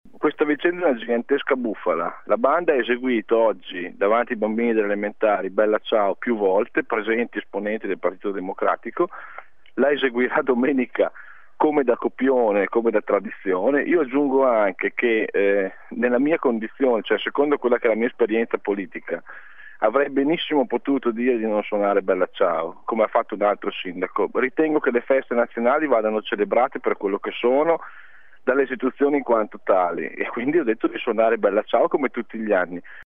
Ascolta il sindaco Caselli